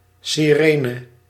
Ääntäminen
IPA: /sirɛnə/